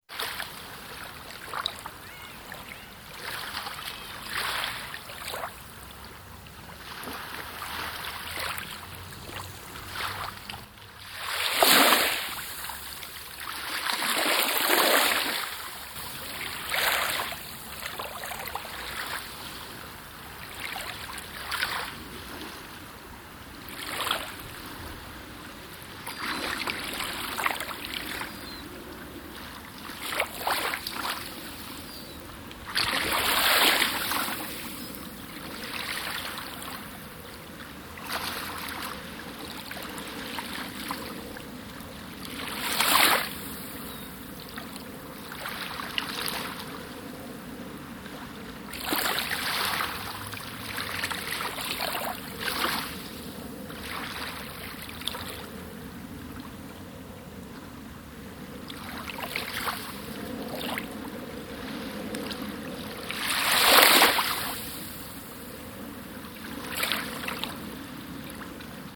Звуки природы - Плеск волн
993_plesk-voln.mp3